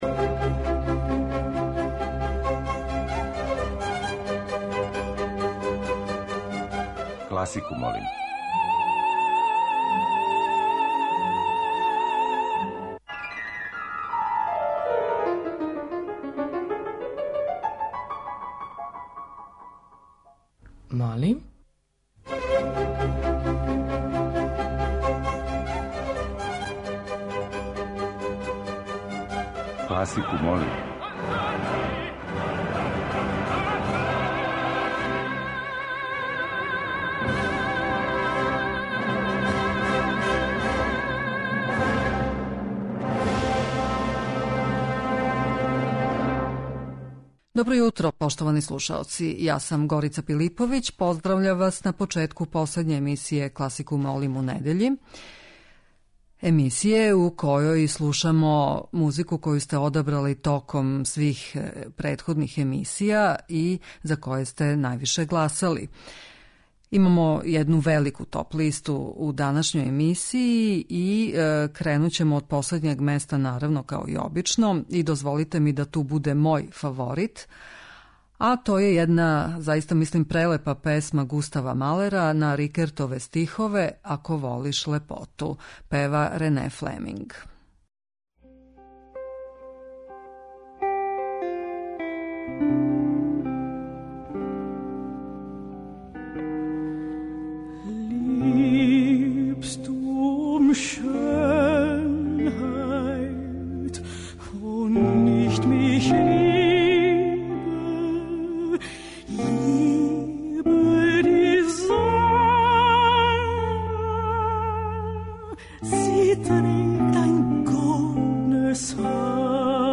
Недељна топ-листа класичне музике Радио Београда 2
Након сабирања гласова које смо од понедељка до четвртка примили путем фиксног и мобилног телефона, е-mail адресе и групе на друштвеној мрежи Facebook, данас емитујемо композиције из опера-бајки које су се највише допале слушаоцима.